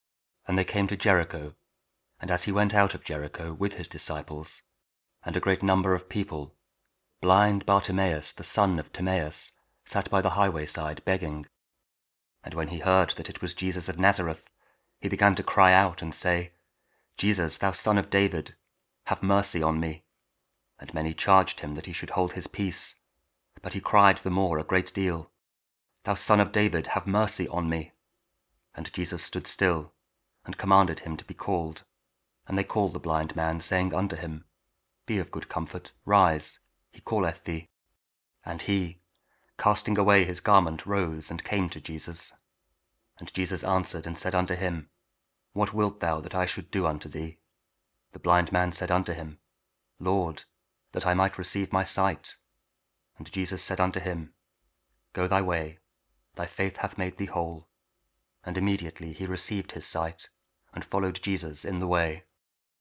Mark 10: 46-52 – 30th Sunday Year B, also Week 8 Ordinary Time, Thursday (King James Audio Bible KJV, King James Version, Spoken Word)